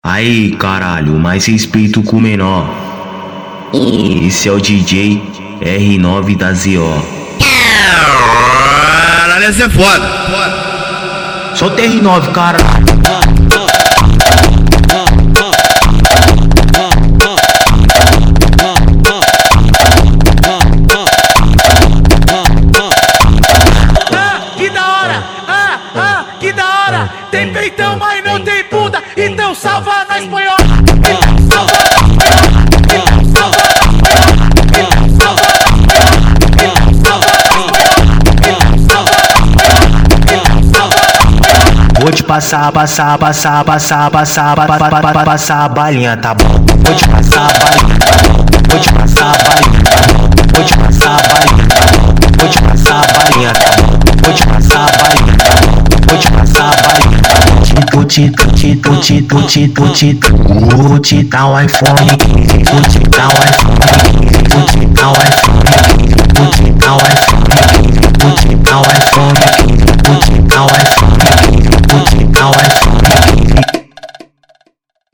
Gênero: Funk